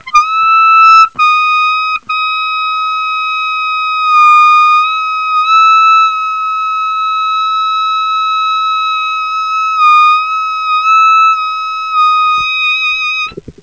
Find the note between E and Eb and sustain.